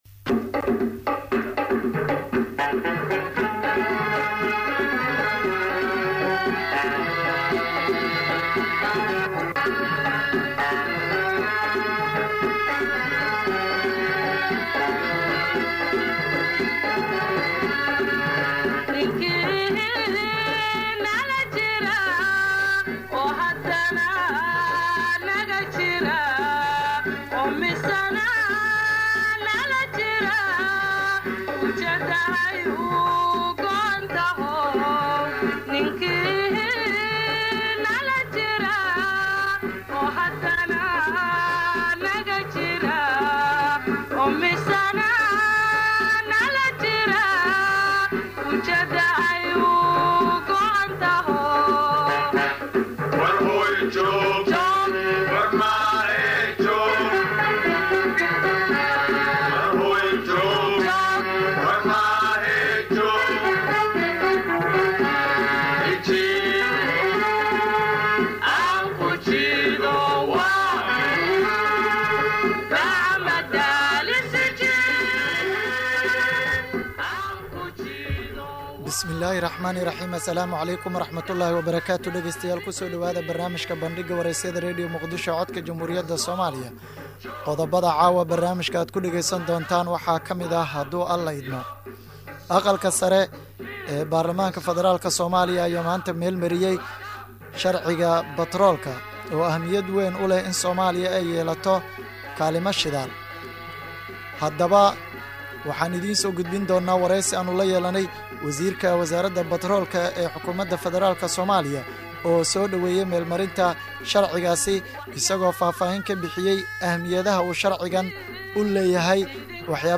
Barnaamijka waxaa diirada lagu saaraa raad raaca ama falanqeynta dhacdooyinka maalintaas taagan, kuwa ugu muhiimsan, waxaana uu xambaarsan yahay macluumaad u badan Wareysiyo.